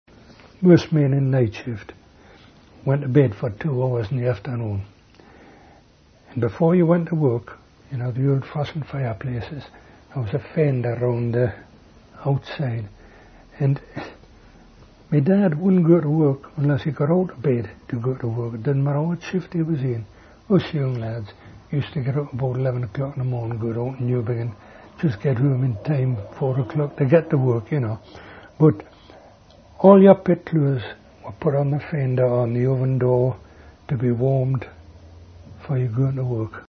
Woodhorn